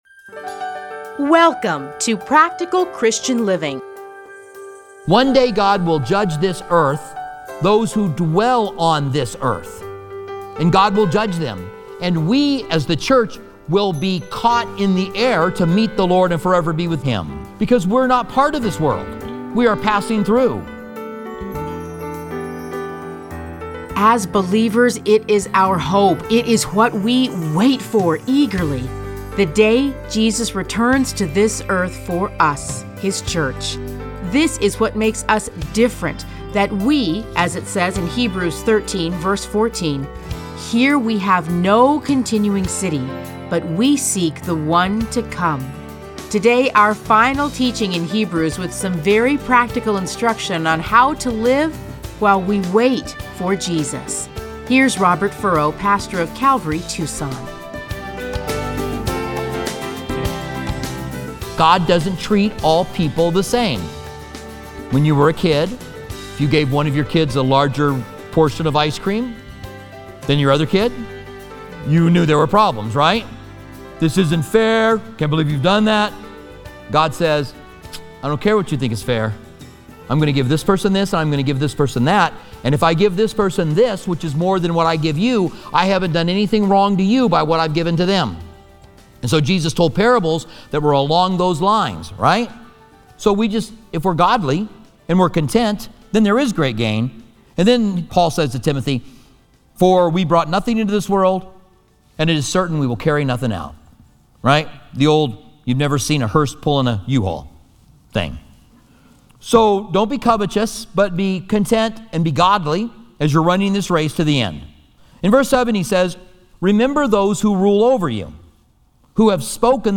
Listen to a teaching from Hebrews 13:1-25.